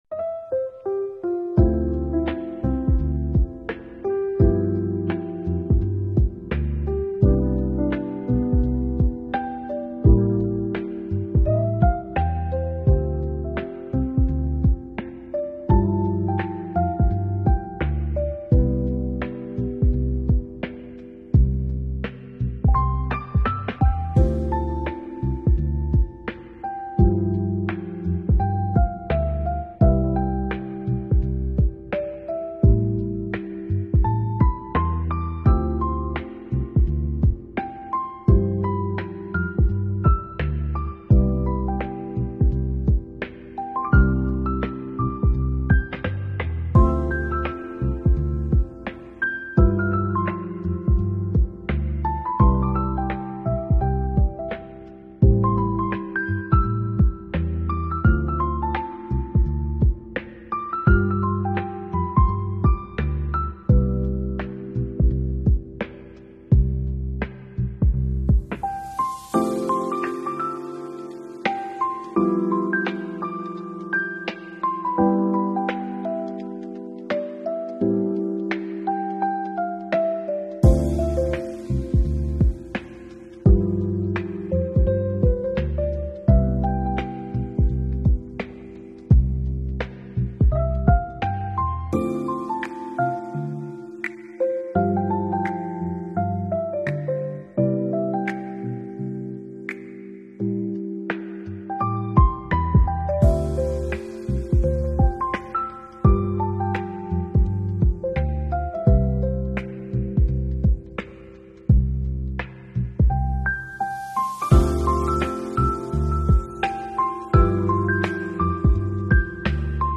Jazz Fusion BGM